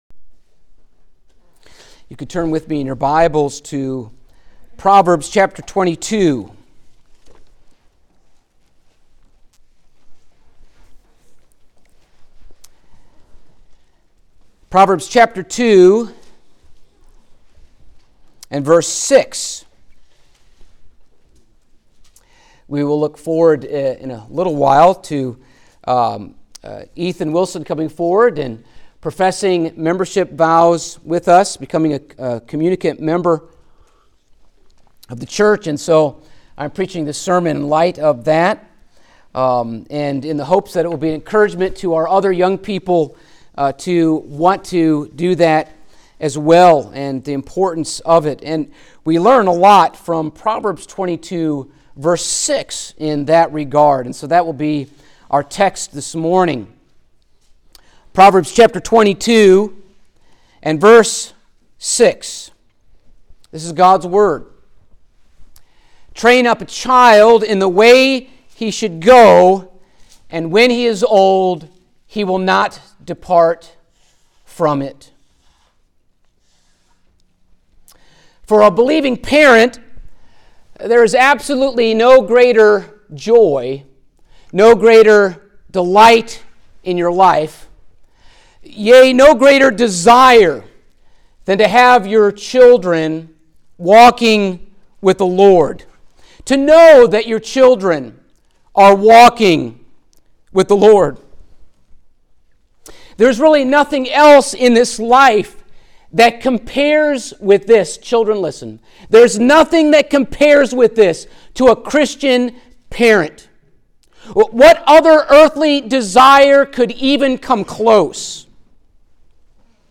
Church Membership Passage: Proverbs 22:6 Service Type: Sunday Morning Topics